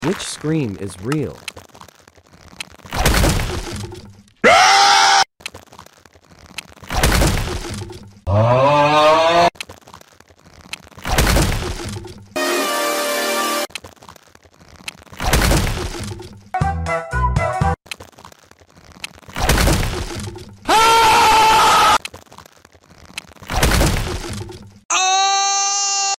Which scream is real?